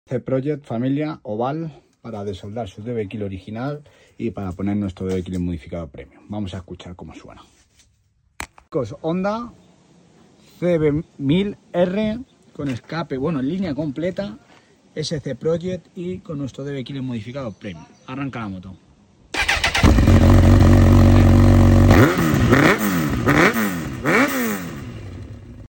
🔊 Más sonido 💥 Más petardeo 😎 Y una sonrisa que no te la quita ni el casco.
🔊 Louder sound 💥 More pops & bangs 😎 And a smile you can’t hide even under the helmet.